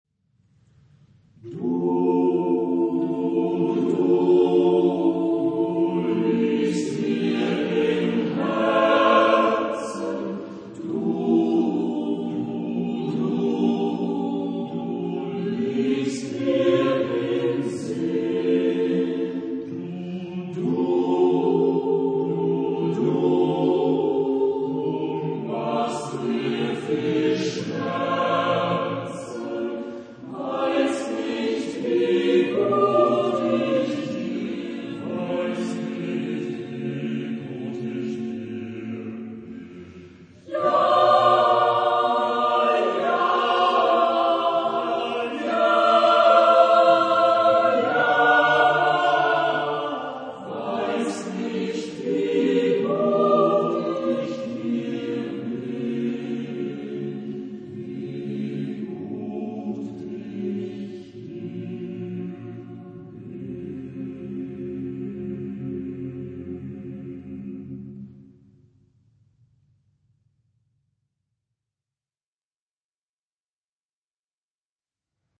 Genre-Stil-Form: Volkslied ; Liedsatz ; weltlich
Chorgattung: SATB  (4 gemischter Chor Stimmen )
Tonart(en): F-Dur